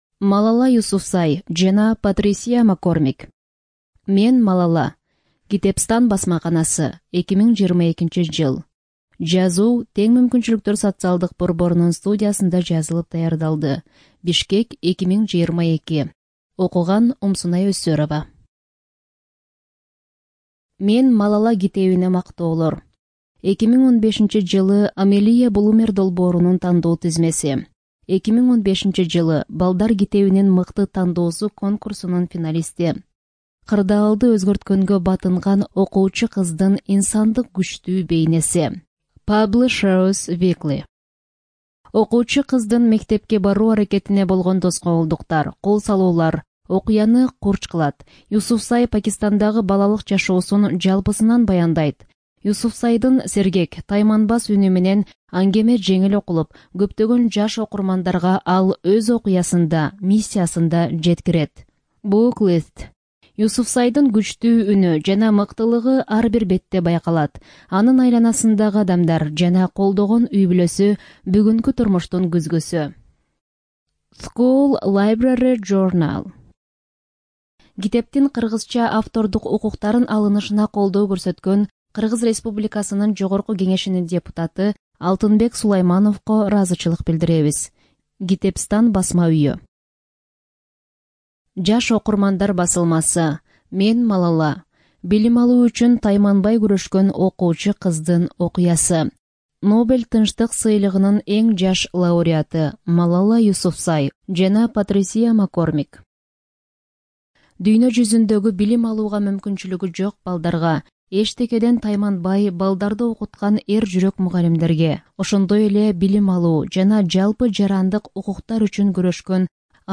Студия звукозаписиСоциальный центр "Равные возможности" (Бишкек)